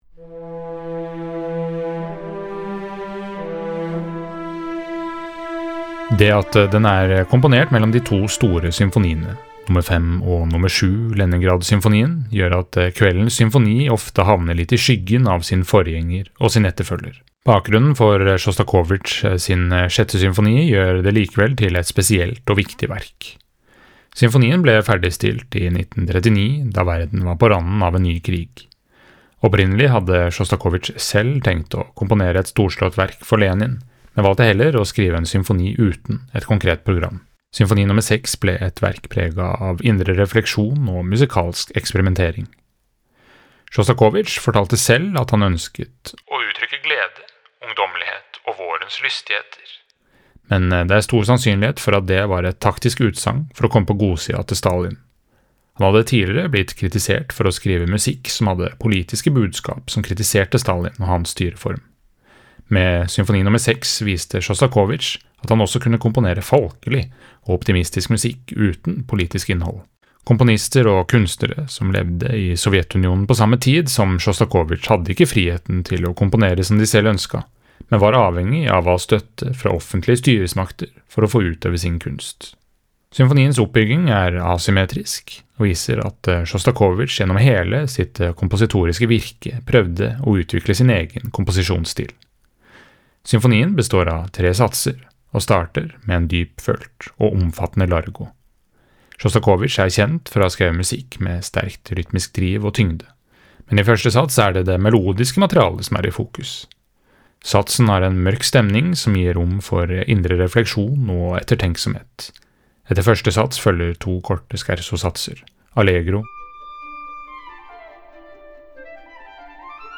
VERKOMTALE: Dmitrj Sjostakovitsjs Symfoni nr. 6
VERKOMTALE-Dmitrj-Sjostakovitsjs-Symfoni-nr.-6.mp3